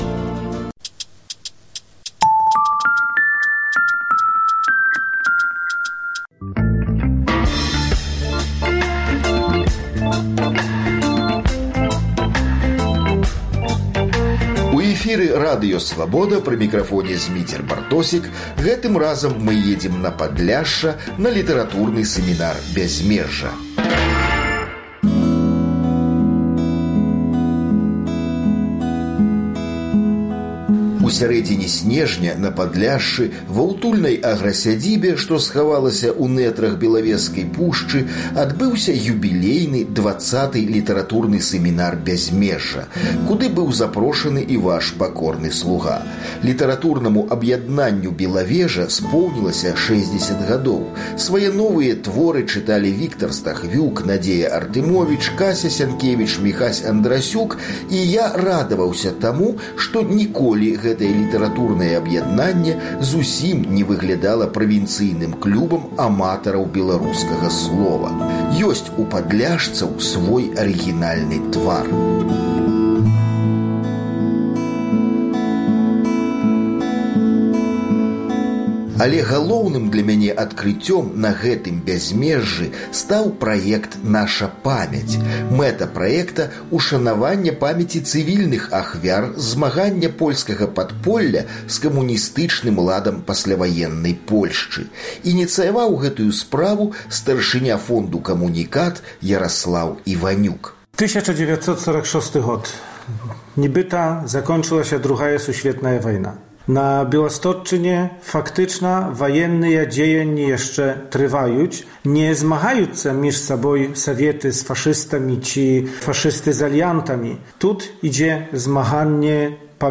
Падарожжа на Падляшша, дзе адбыўся літаратурны сэмінар "Бязьмежжа". Гутарка